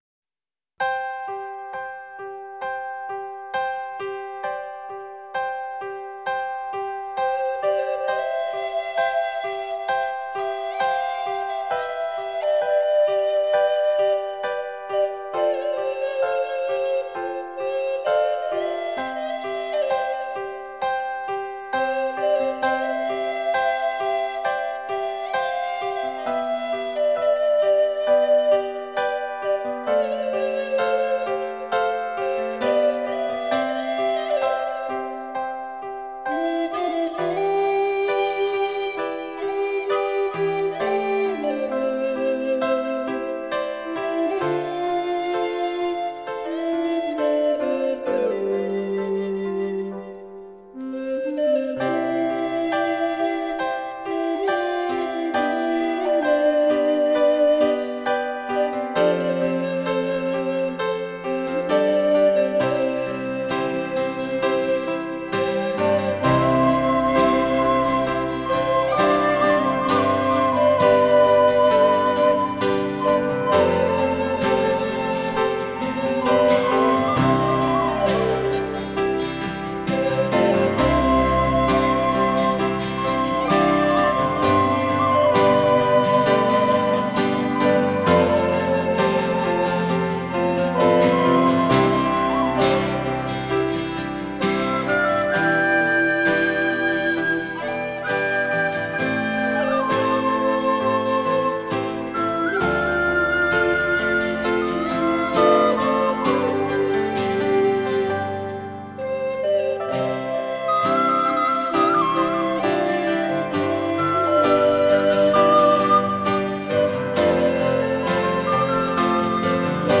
0156-长笛 短笛名曲玫瑰.mp3